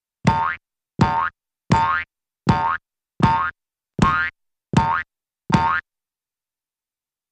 Object Bounces ( I.e. Pogo Stick Or Hopping ) 8x ( Jews Harp & Drum ).